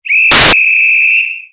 Station Departure Whistle  Ref
depart_steam_whistle.wav